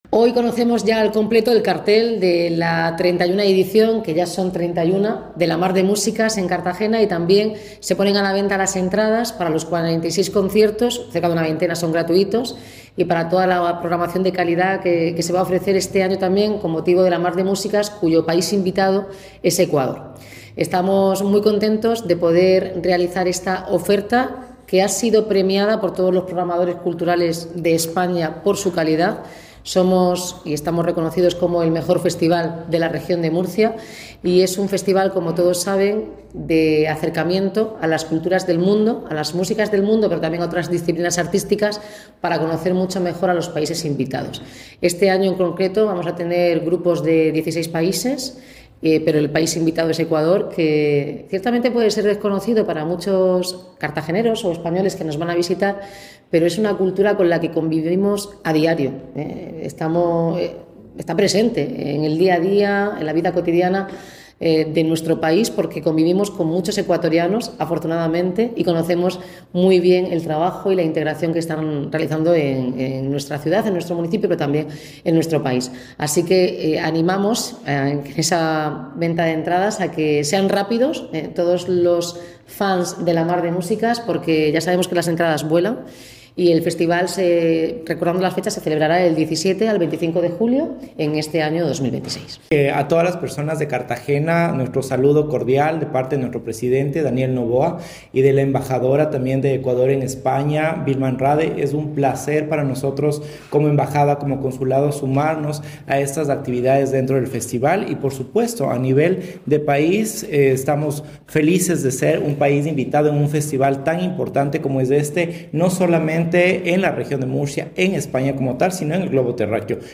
Enlace a Declaraciones de la alcaldesa, Noelia Arroyo, y el cónsul de Ecuador, César Mantilla